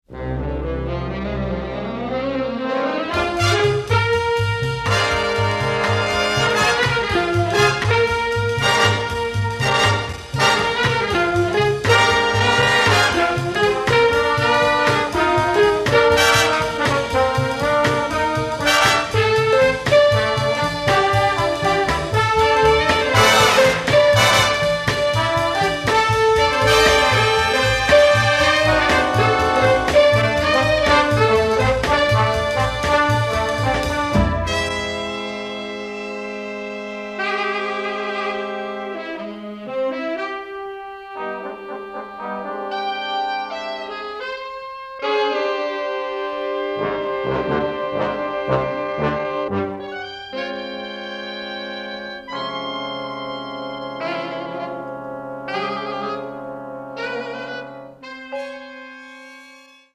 shifting woodwind lines, big band-flavored fight music